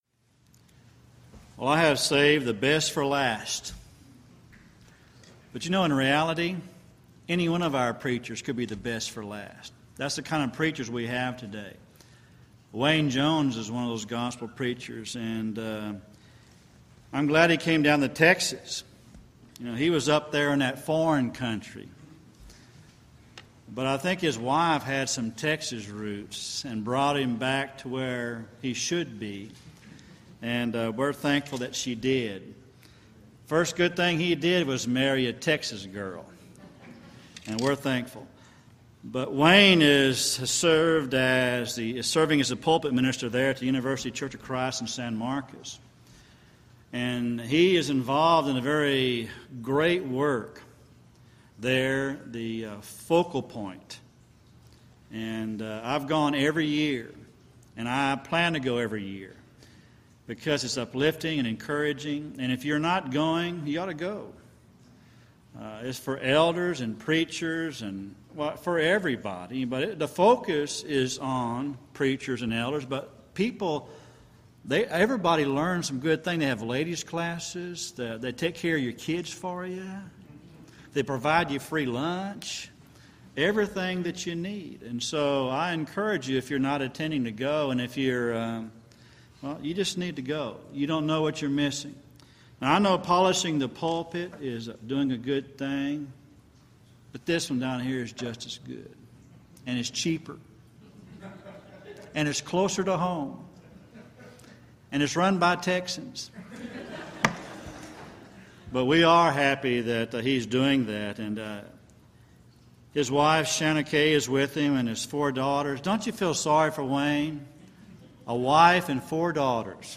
Event: 3rd Annual Back to the Bible Lectures
lecture